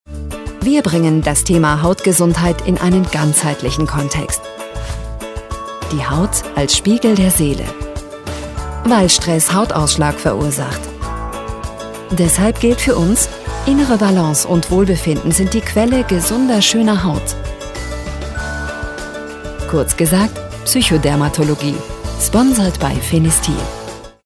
Ausgebildete Sprecherin mit eigenem Studio!
Sprechprobe: Werbung (Muttersprache):